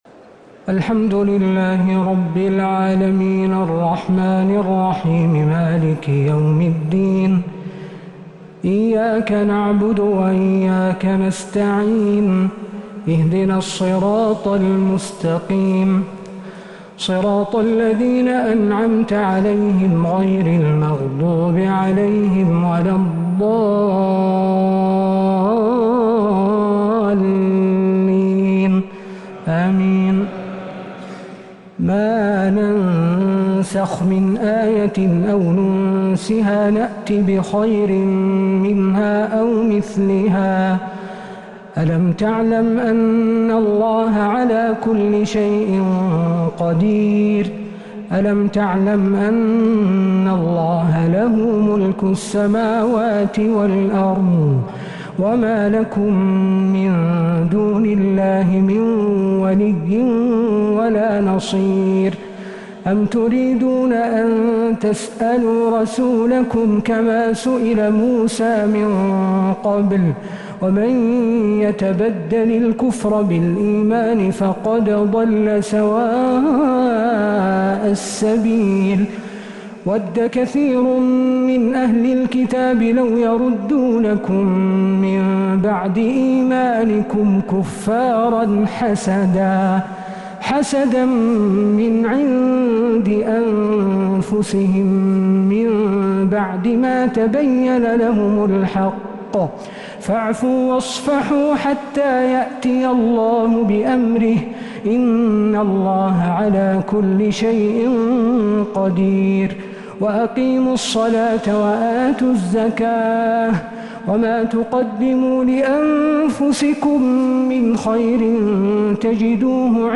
تراويح ليلة 2 رمضان 1447هـ من سورة البقرة {106-167} Taraweeh 2nd night Ramadan 1447H > تراويح الحرم النبوي عام 1447 🕌 > التراويح - تلاوات الحرمين